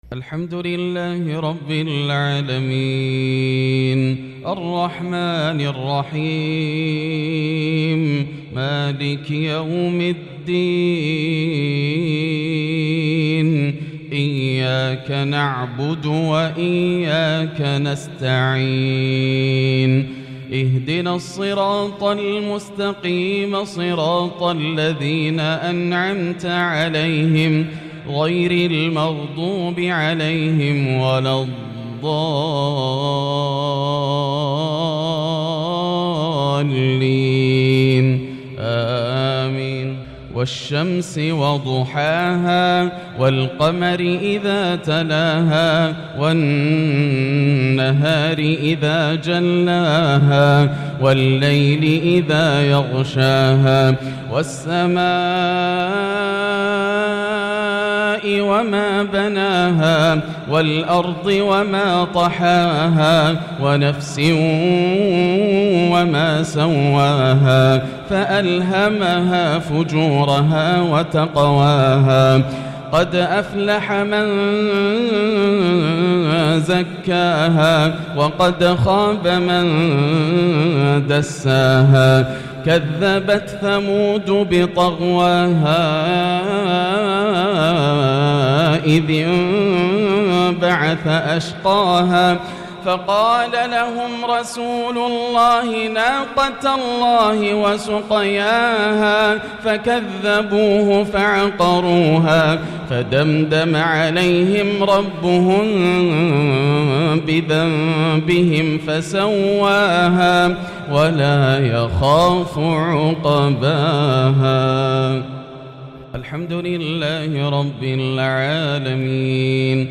مغرب الثلاثاء 7-7-1443هـ سورتي الشمس و القارعة | Maghrib prayer Surat Ash-shams and Al-Qaari'a 8-2-2022 > 1443 🕋 > الفروض - تلاوات الحرمين